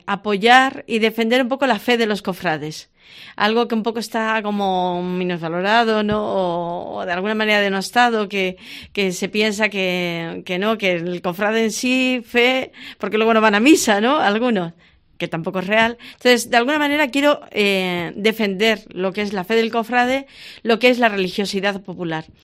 pregón de la Semana Santa de Logroño